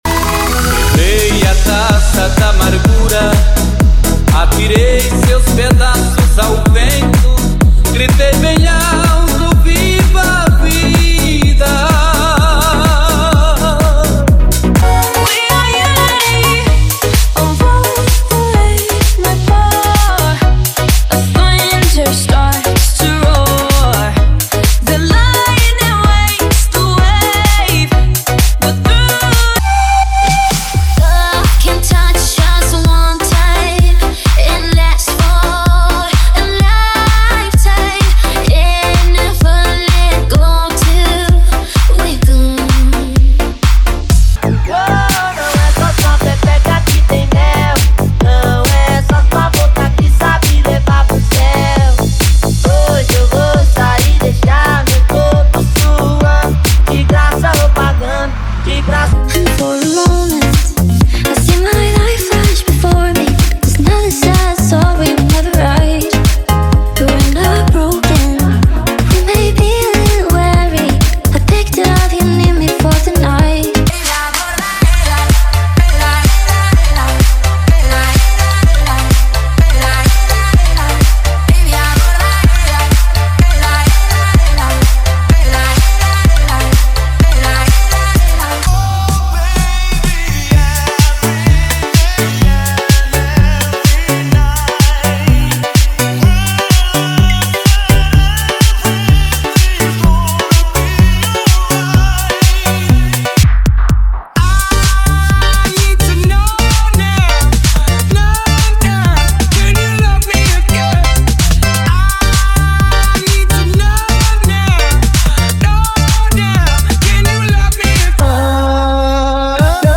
• Sem Vinhetas
• Em Alta Qualidade